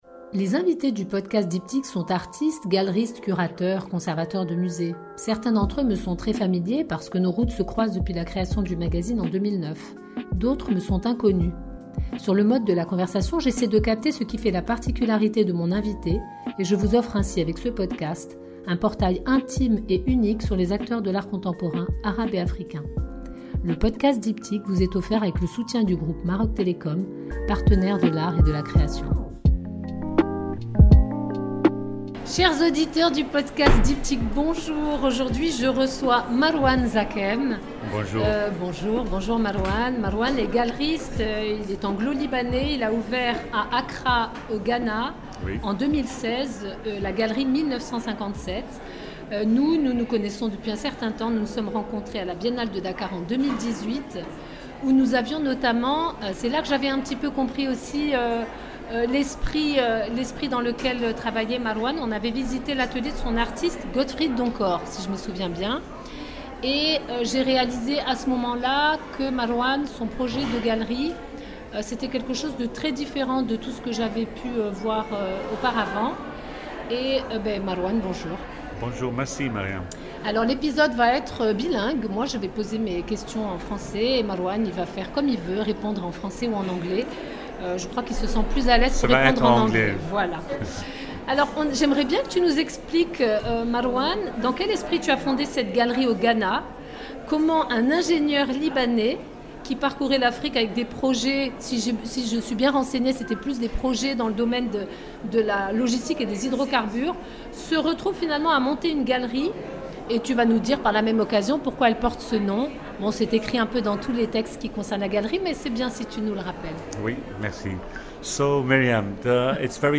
Cet épisode a été enregistré en Février 2024 à La Mamounia à l'occasion de la foire d'art contemporain africain 1-54, avec Maroc Télécom, partenaire des arts et de la création.